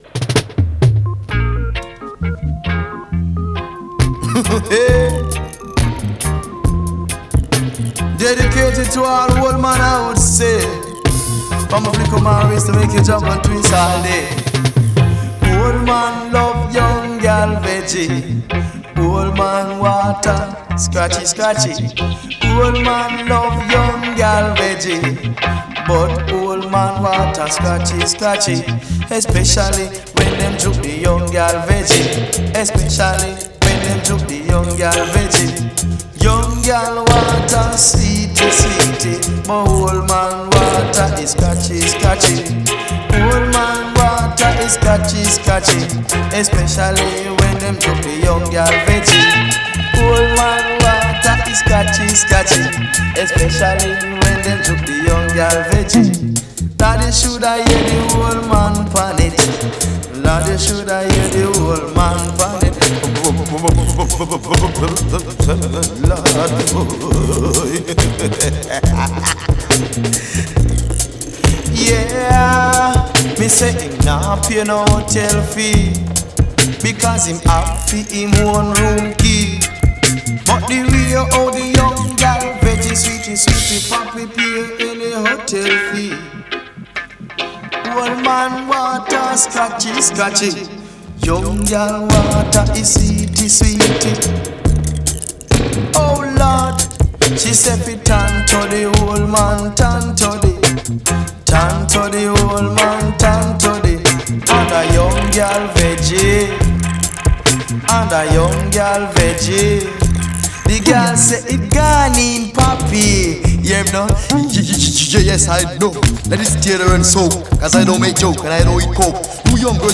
proporcionando los ritmos masivos pero claros
reggae y dancehall